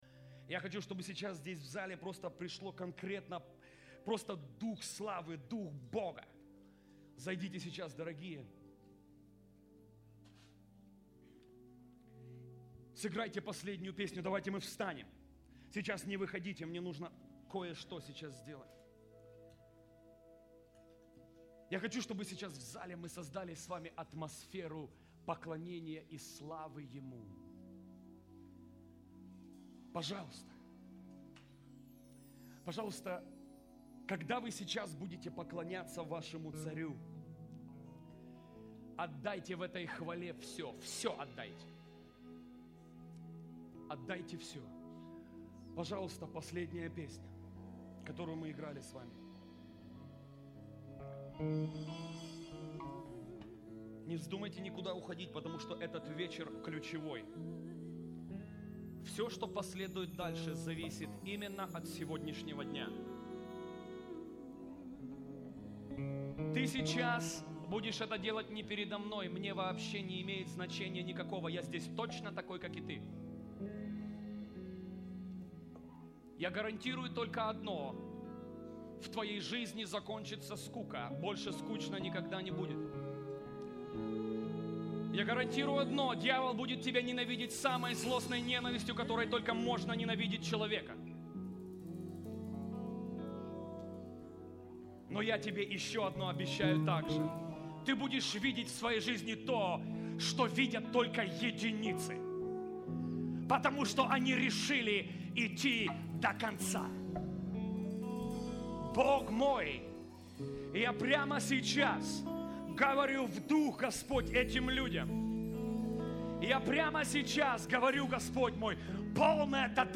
iš konferencijos